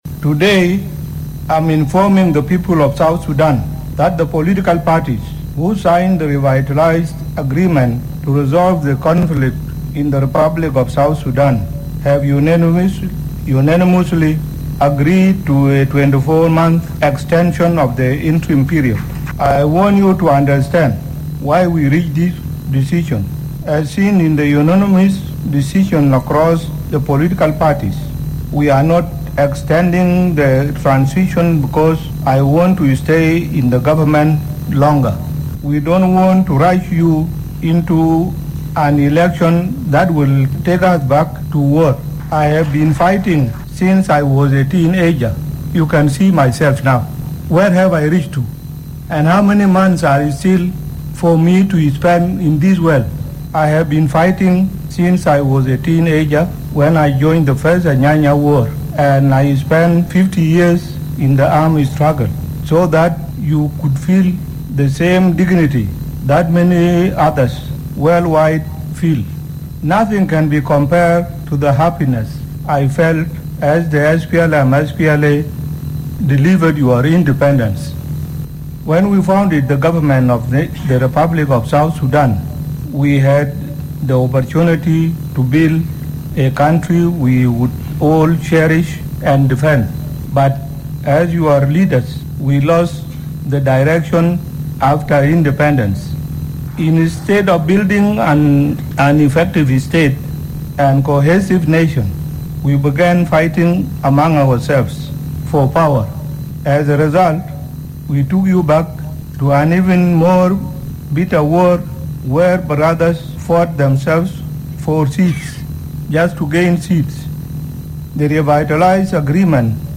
In his speech at the unveiling of the road map, President Salva Kiir said the intention of the extension is not to stay longer in power, but rather to prepare adequately for national elections. We bring you President Kiir’s 7” speech.